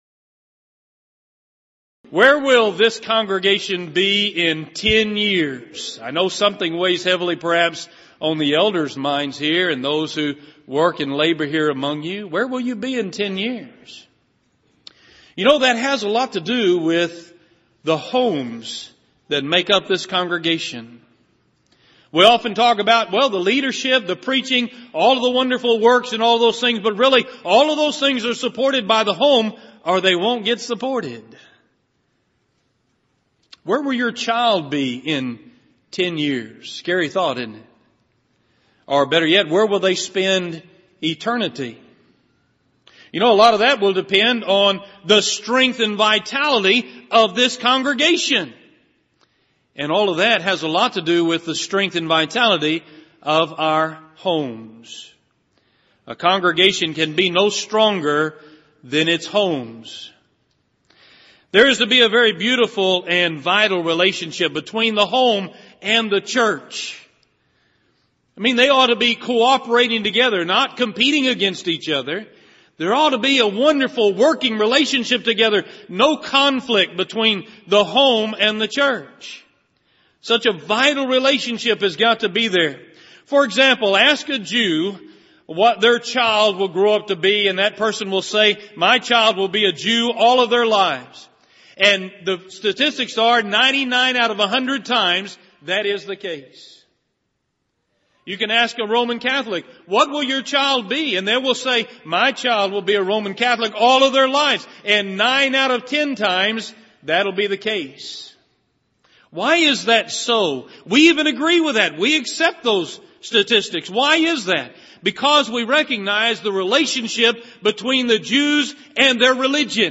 Series: Southwest Lectures Event: 28th Annual Southwest Lectures Theme/Title: Honoring Christ: Calling For Godly Homes